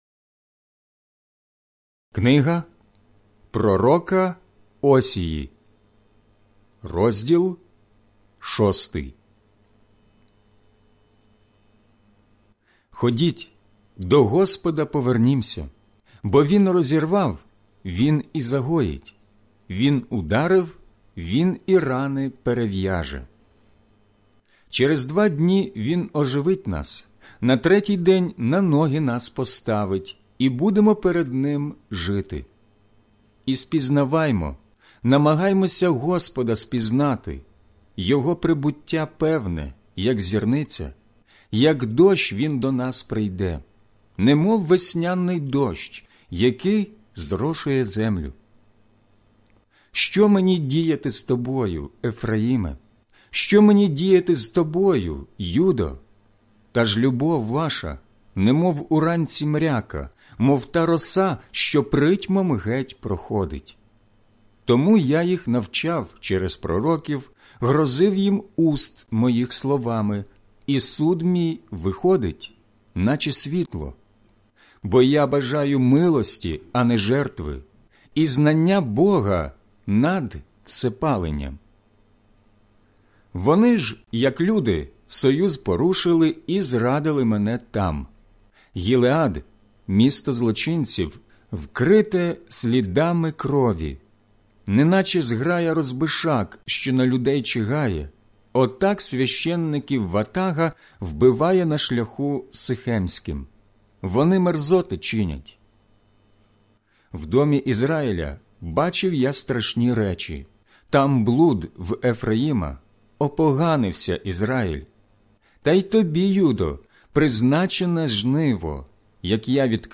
аудіобіблія